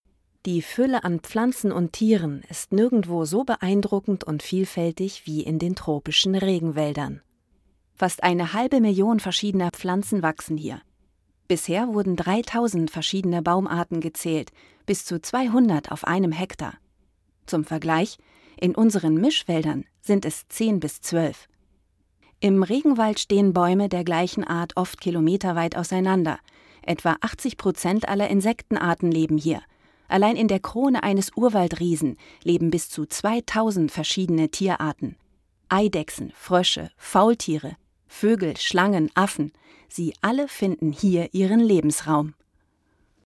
Dokumentation
Doku, Tutorial